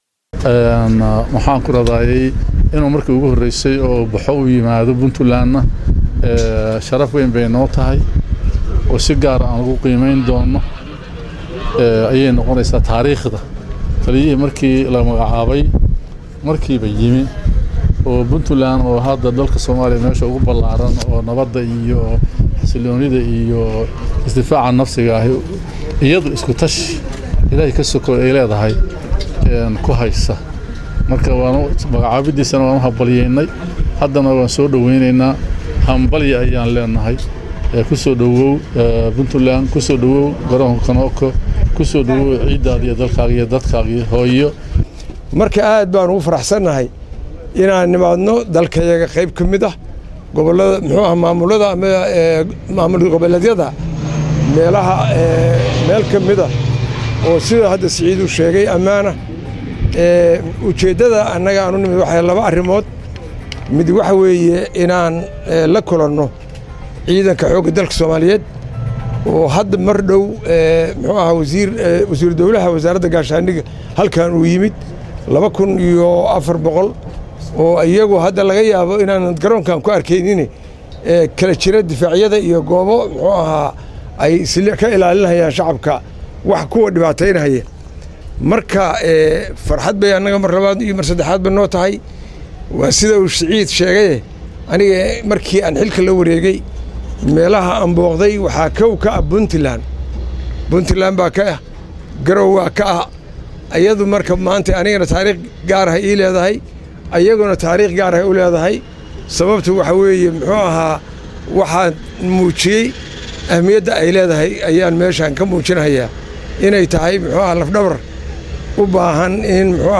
Labada taliye ayaa si wadjir ah saxaafadda ula hadlay,waxaana hadalka ku hormaray gen.Siciid Maxammed Xirsi(Siciid Dheere) oo sheegay in Puntland tahay halka ugu ballaaran Soomaaliya”oo nabadda iyo xasiloonida iyo is-difaaca nafsiga ah ka jiro“,taasoo ay ku gaartay”isku-tashi iyadu leedahay Ilaahay ka sokoow”,sida uu hadalka u dhigay.
Dhagayso codadka Taliyayaasha & madaxweyne ku xigeenka Puntland